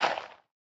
Minecraft Version Minecraft Version 1.21.5 Latest Release | Latest Snapshot 1.21.5 / assets / minecraft / sounds / block / composter / fill2.ogg Compare With Compare With Latest Release | Latest Snapshot